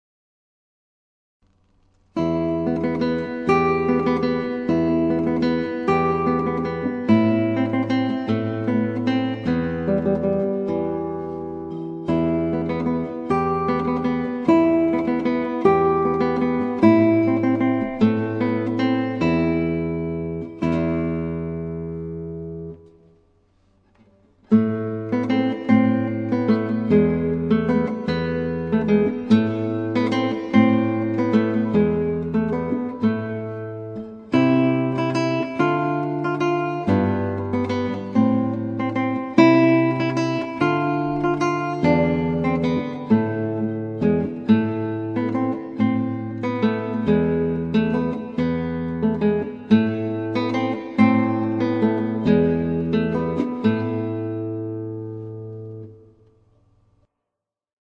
Solo gitaar
• Instrumenten: Gitaar Solo